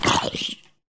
zombiehurt1